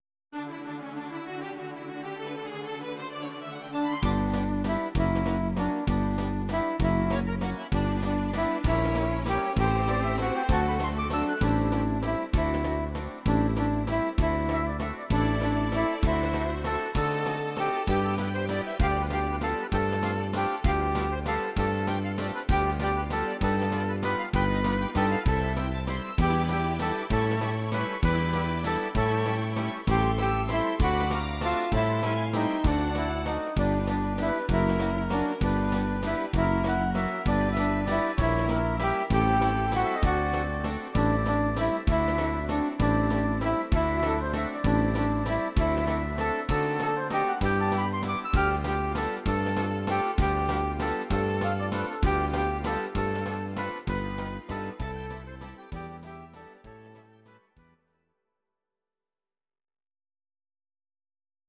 These are MP3 versions of our MIDI file catalogue.
Please note: no vocals and no karaoke included.
Musette Walzer